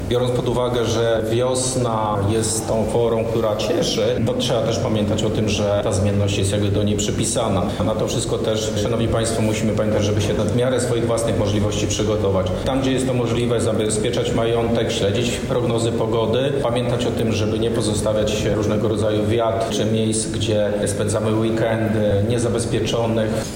Dziś (07.04) odbył się briefing prasowy w Lubelskim Urzędzie Województwa w Lublinie poświęcony sytuacji pogodowej z ostatnich 48 godzin.
Wojciech Wołoch– mówi Wicewojewoda Lubelski Wojciech Wołoch.